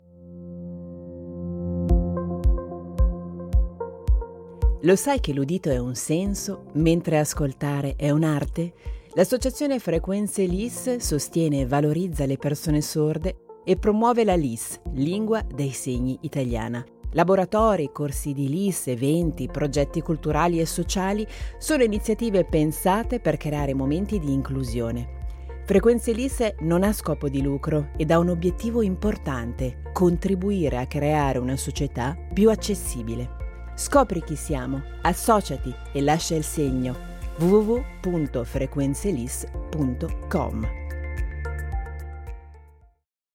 Spot radiofonico Associazione frequenzeLIS
SPOT-RADIO-MORCOTE.mp3